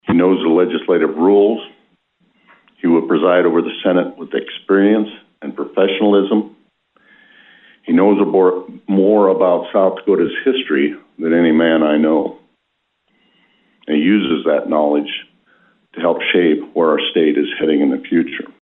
Rhoden says the Sioux Falls lawmaker has an extensive background in state government.